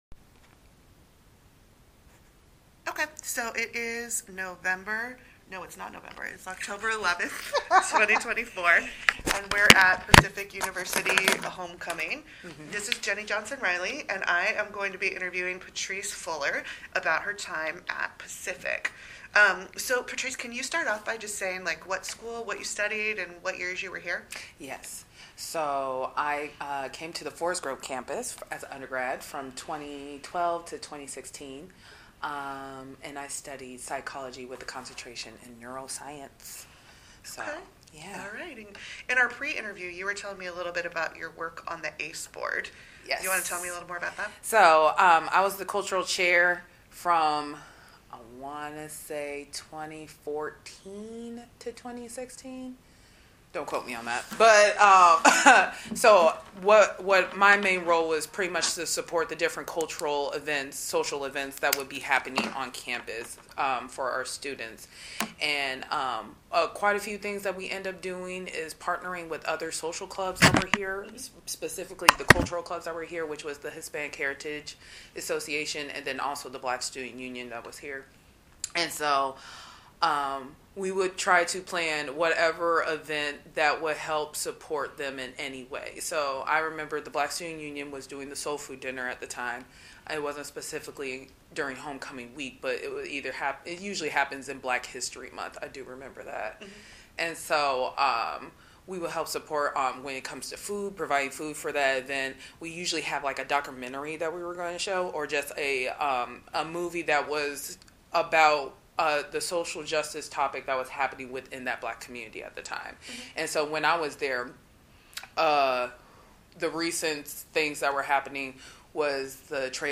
This is one of a group of recordings made during a reunion in October, 2024.
Washington County Oral Histories